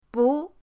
bu2.mp3